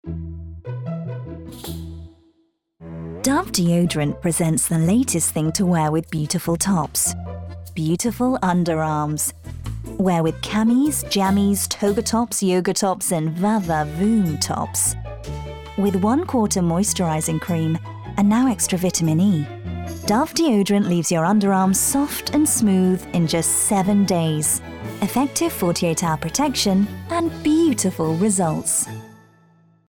30/40's Neutral/RP, Warm/Versatile/Bright
Commercial Showreel